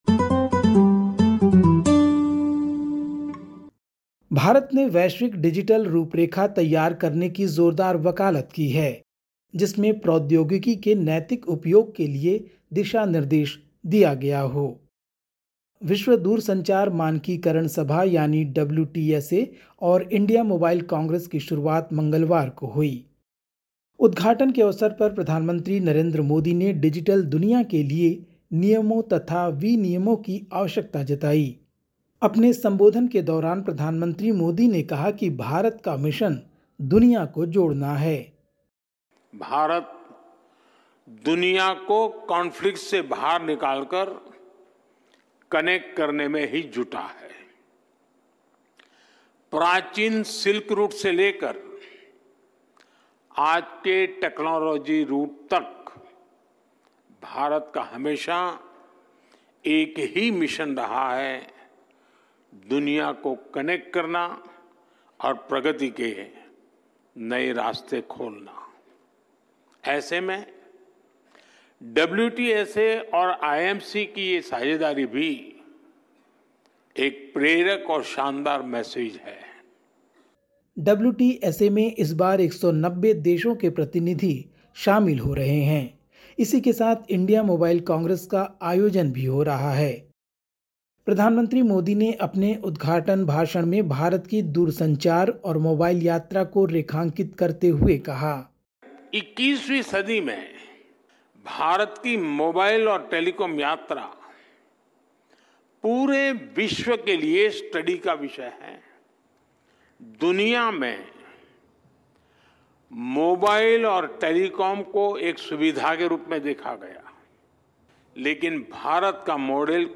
Listen to the latest SBS Hindi news from India. 16/10/2024